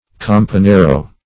Search Result for " campanero" : The Collaborative International Dictionary of English v.0.48: Campanero \Cam`pa*ne"ro\, n. [Sp., a bellman.]